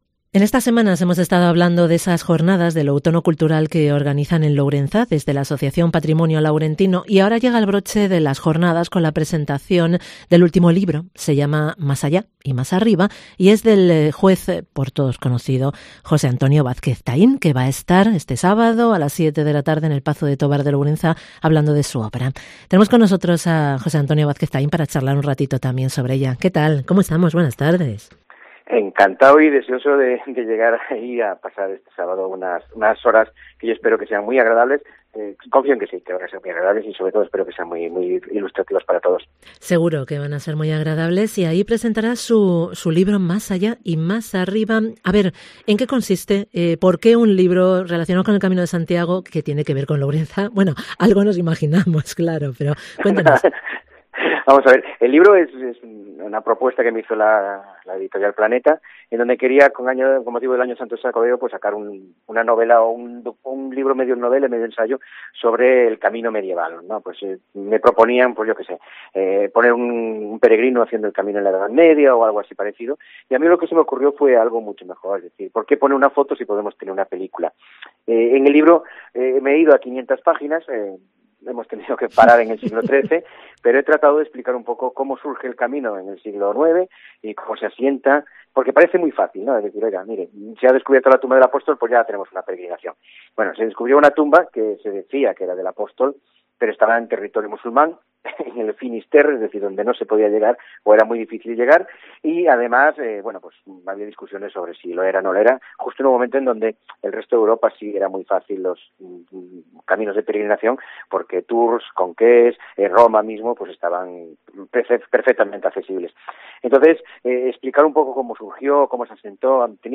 Entrevista con JOSÉ ANTONIO VÁZQUEZ TAÍN